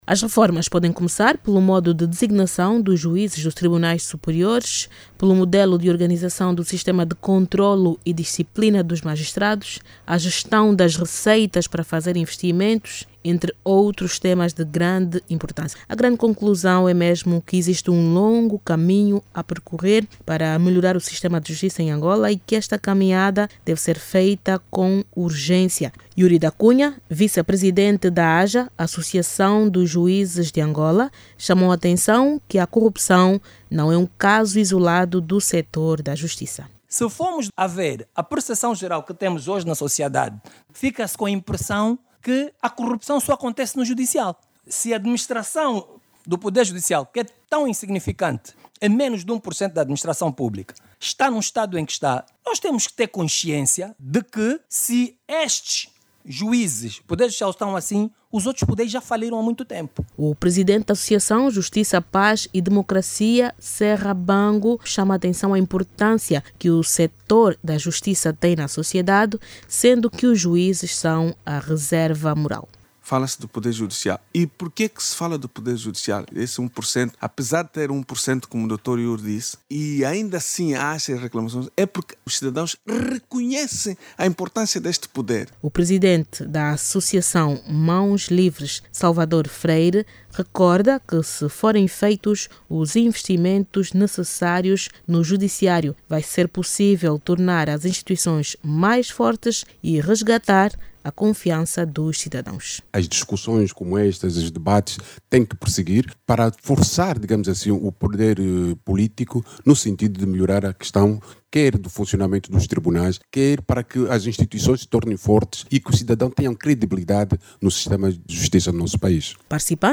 O estado da justiça em Angola esteve ontem, sábado(20), em debate na Rádio Nacional. Os especialistas consideram que ainda há um longo caminho a percorrer para a melhoria do sistema de justiça no país e defendem reformas urgentes.